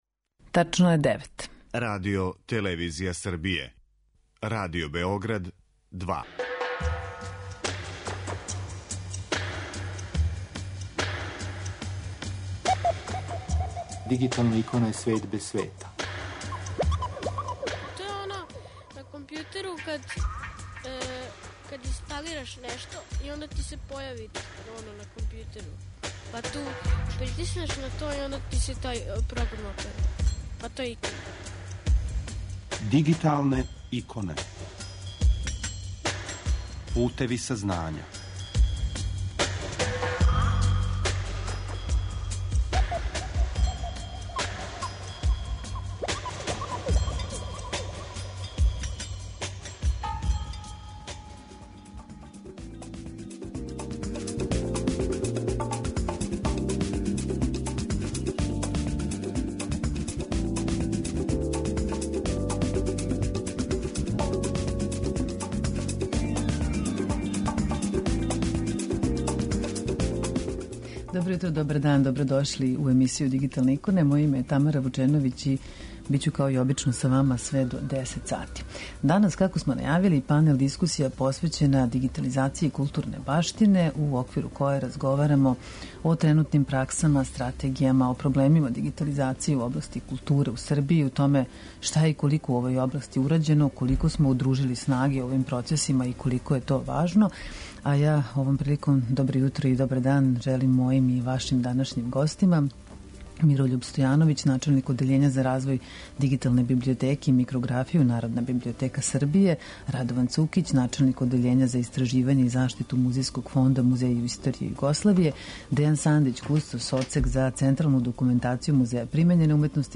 У данашњој емисији слушамо панел-дискусију посвећену дигитализацији културне баштине у оквиру које разговарамо о тренутним праксама, стратегијама и проблемима дигитализације у области културе у Србији, о томе шта је и колико урађено, колико смо "удружили снаге" у овим процесима?